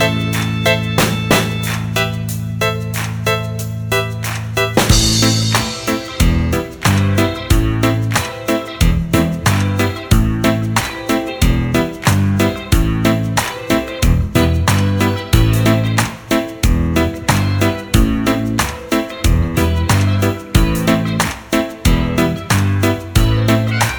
no Backing Vocals Pop (1980s) 4:43 Buy £1.50